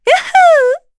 Yuria-Vox_Happy4_kr.wav